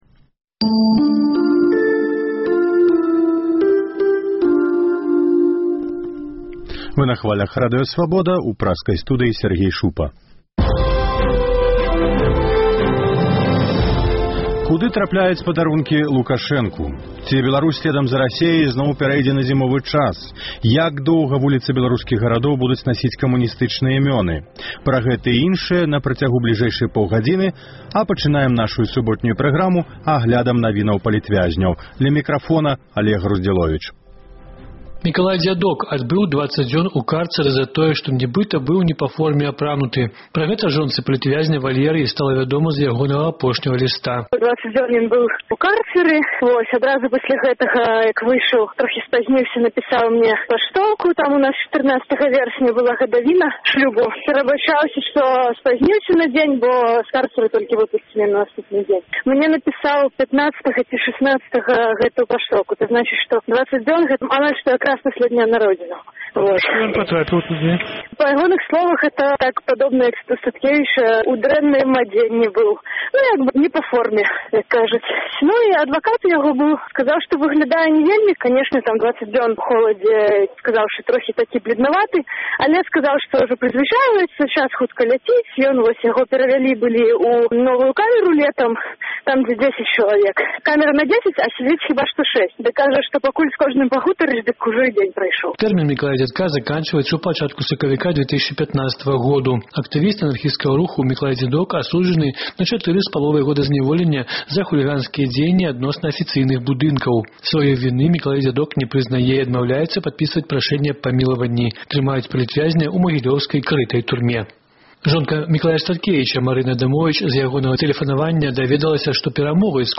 Паведамленьні нашых карэспандэнтаў, госьці ў жывым эфіры, званкі слухачоў, апытаньні ў гарадах і мястэчках Беларусі.